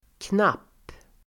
Uttal: [knap:]